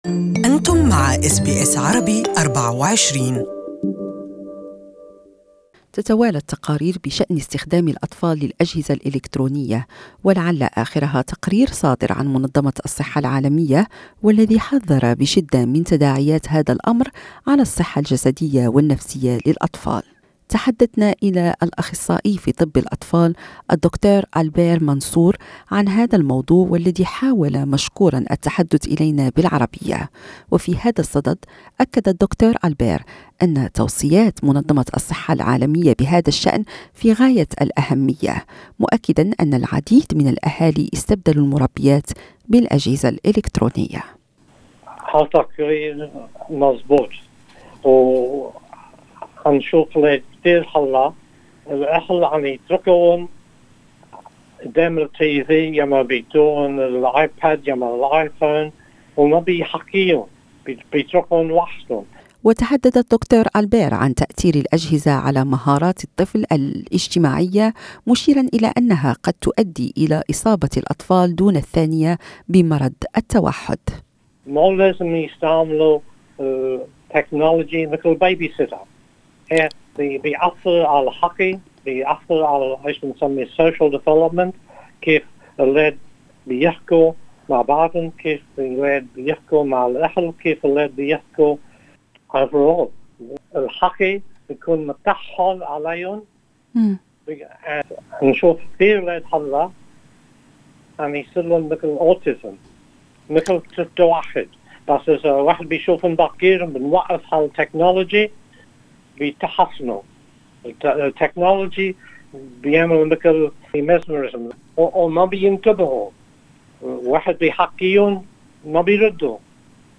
This interview is in Arabic language.